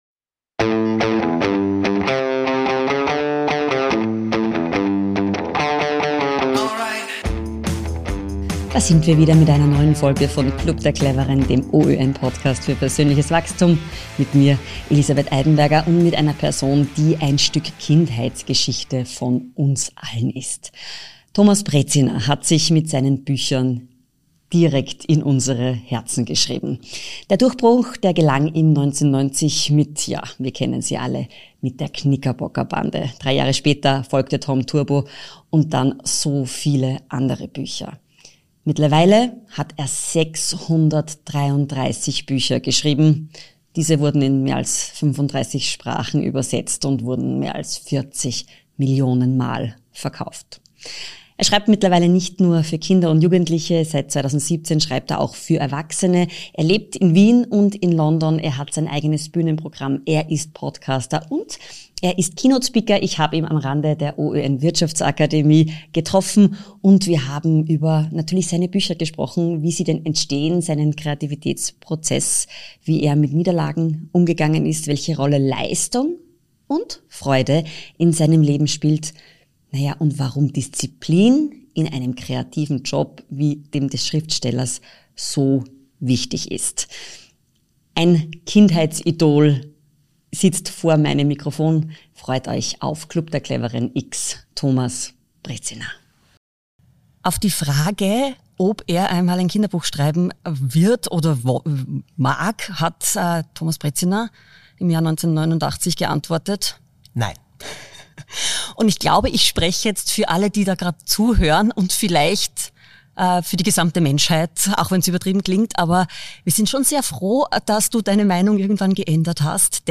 Im der neuen Podcast-Folge von "Club der Cleveren" sprach Kinder- und Jugendbuchautor Thomas Brezina über Kreativität versus Disziplin und warum ihn selbst nach 633 geschriebenen Büchern noch Selbstzweifel plagen.